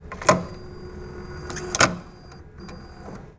Index of /server/sound/arcbank/atm
cardinsert_fast.wav